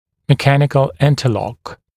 [mɪ’kænɪkl ˌɪntə’lɔk][ми’кэникл ˌинтэ’лок]механическое сцепление, зацепление